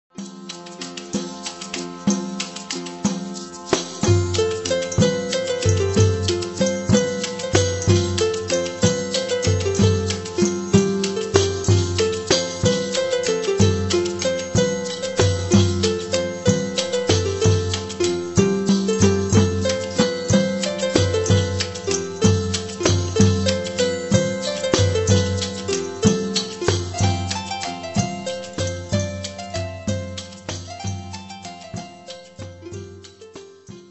fiddle
bass
drums/perc.
guitar&percussion